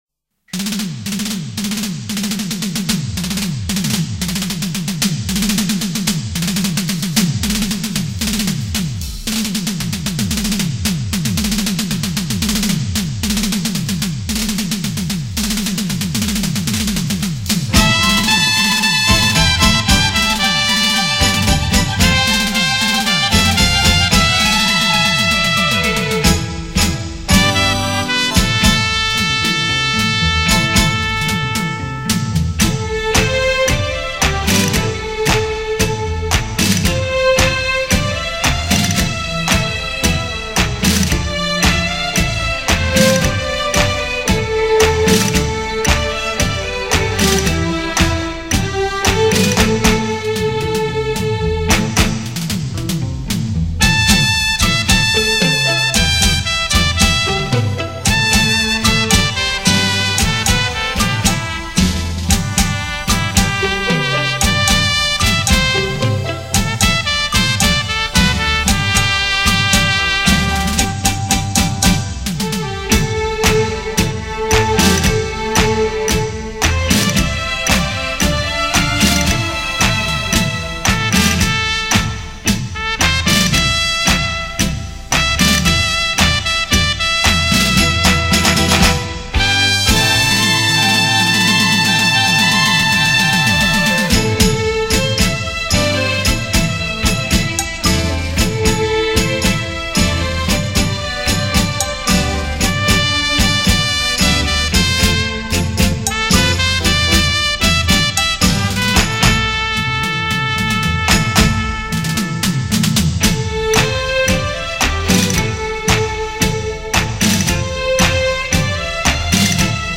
音乐类型： 轻音乐　　　　　    　  　.